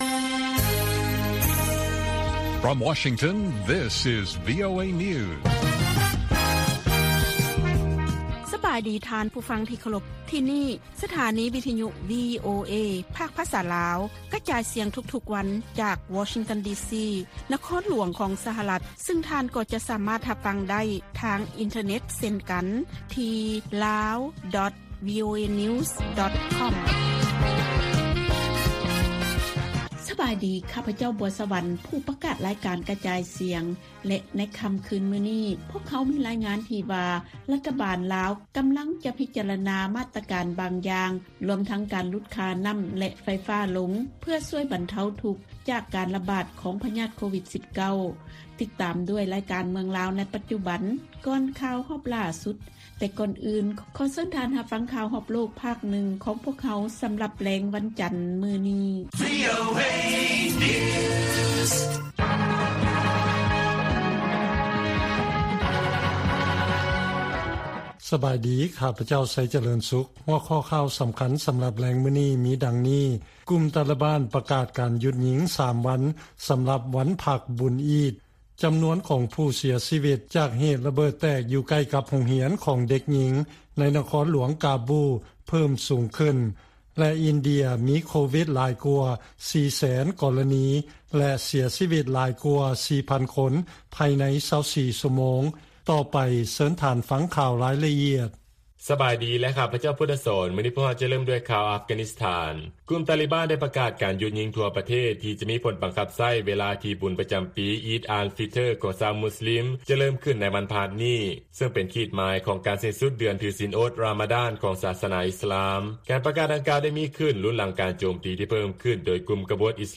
ວີໂອເອພາກພາສາລາວ ກະຈາຍສຽງທຸກໆວັນ. ຫົວຂໍ້ຂ່າວສໍາຄັນໃນມື້ນີ້ມີ: 1) ລາວຈະດຳເນີນຄະດີ ເພື່ອລົງໂທດທາງອາຍາ ກັບແມ່ຍິງລາວ 2 ຄົນ ທີ່ເປັນສາເຫດຕົ້ນຕໍ ການລະບາດຂອງໂຄວິດ-19 ຮອບໃໝ່. 2) ລັດຖະບານລາວ ຫລຸດລາຄາໄຟຟ້າ ແລະເກັບພາສີ ເພື່ອຊ່ວຍບັນເທົາ ໄວຣັສໂຄໂຣນາ.